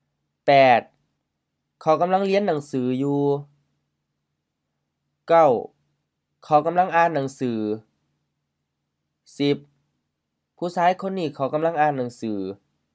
Notes: sentence-final: often with rising tone which is likely a Thai influence